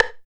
62 LO STIK-R.wav